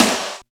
SNR XC.SNR00.wav